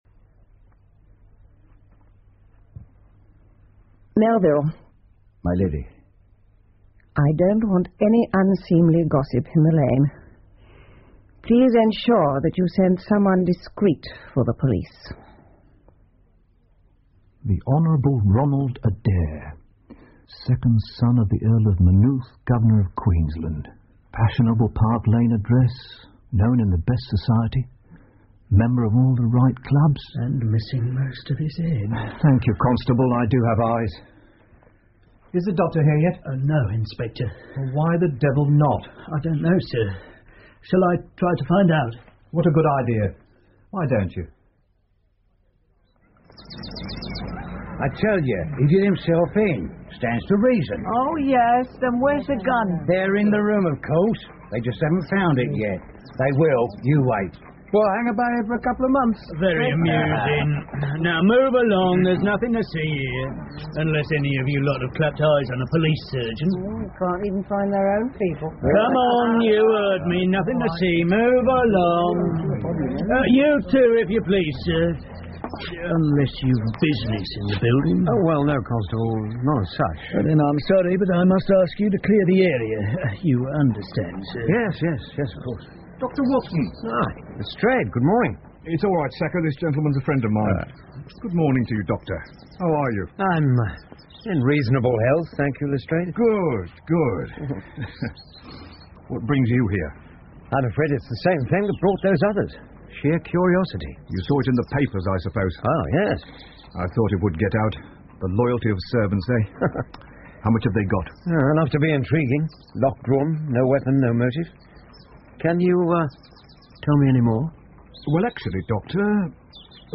福尔摩斯广播剧 The Empty House 3 听力文件下载—在线英语听力室